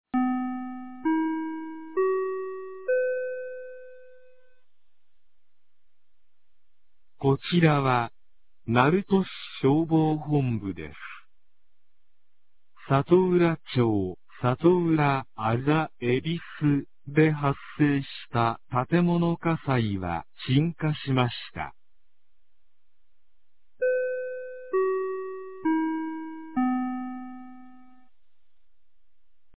2025年02月24日 01時24分に、鳴門市より瀬戸町へ放送がありました。